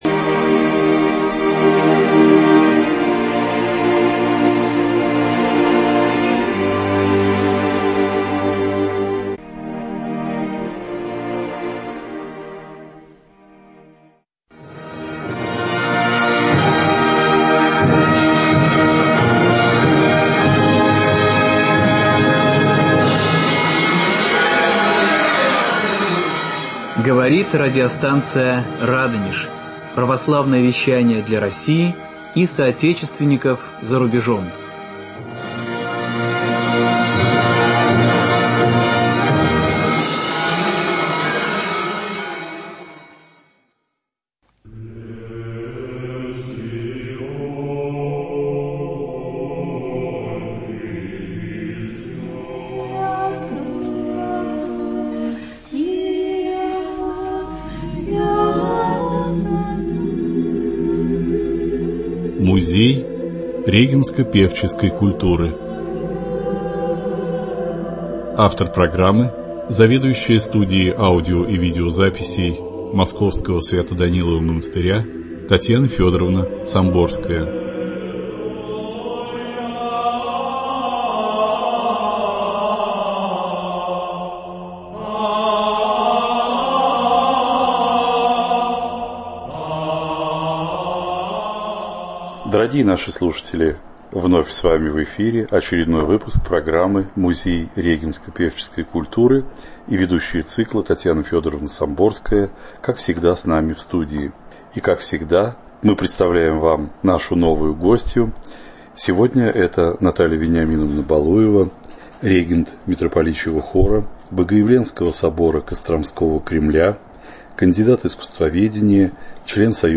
В эфире беседа